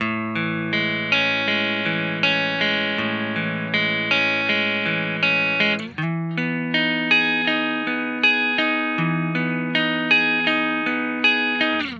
Index of /DESN275/loops/Loop Set - Guitar Hypnosis - Dream Pop Guitar
Love_80_A_DryGuitarArp.wav